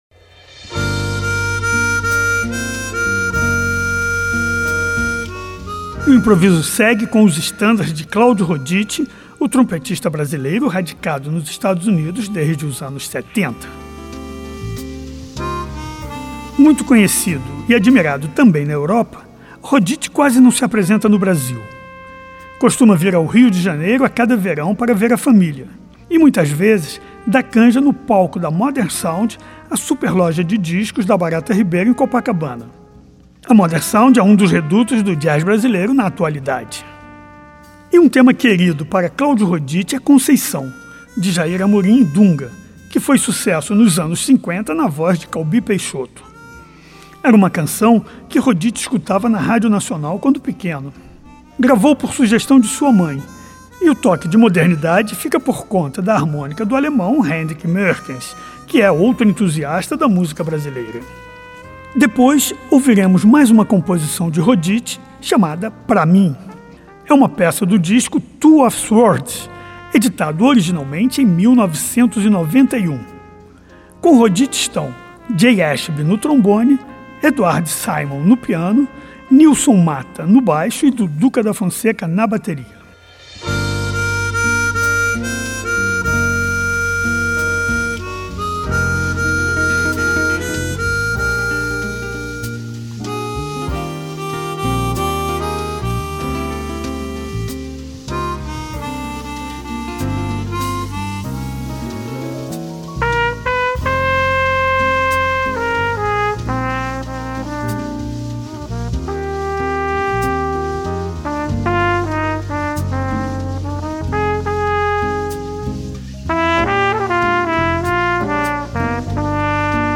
trompetista